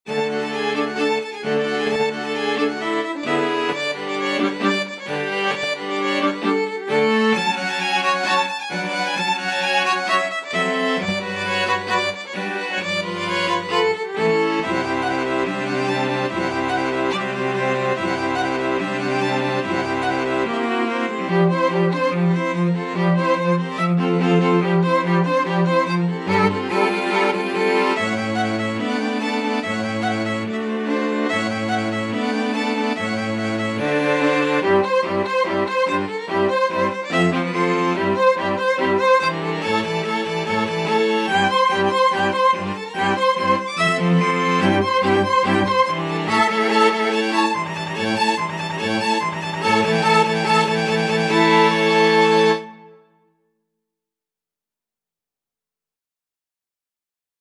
Violin 1Violin 2ViolaCello
L'istesso tempo =132
2/4 (View more 2/4 Music)
Classical (View more Classical String Quartet Music)